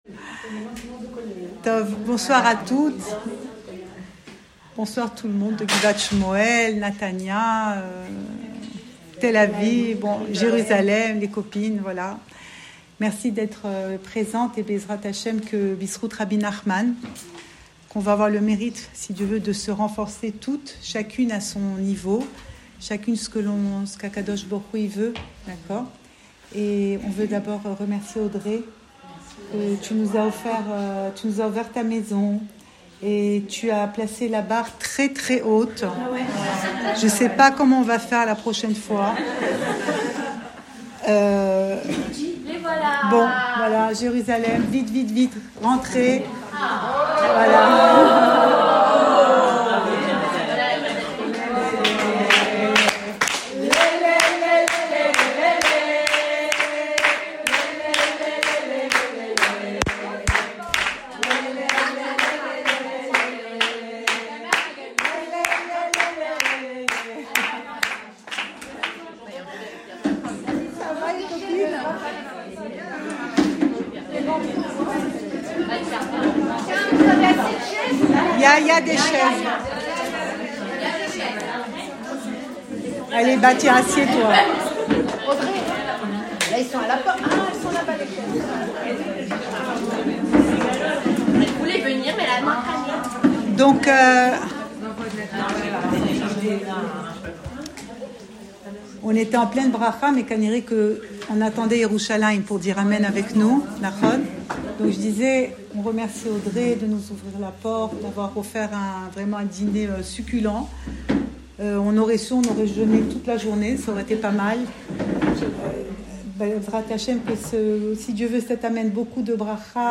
Cours audio Le coin des femmes Le fil de l'info Pensée Breslev - 7 décembre 2025 8 décembre 2025 Hannouka : éduquer notre esprit. Enregistré à Guivat Chmouel